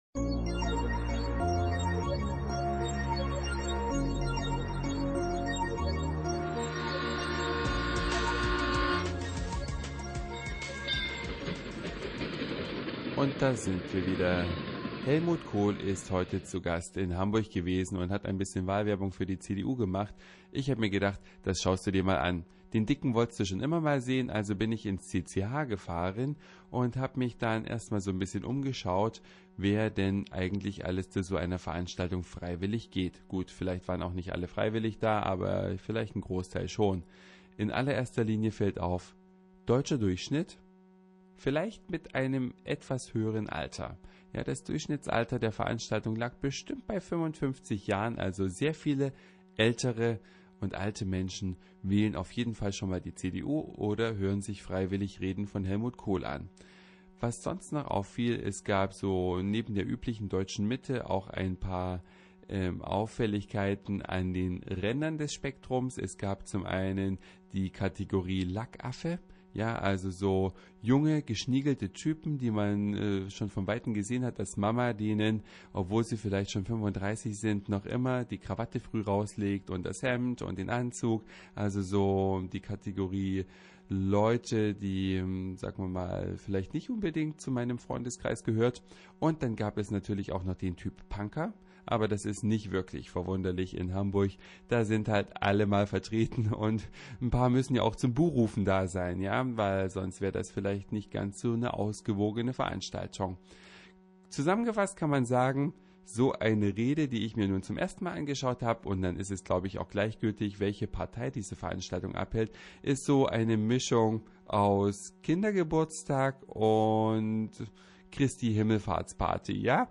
Helmut Kohl war in Hamburg und hielt eine Rede.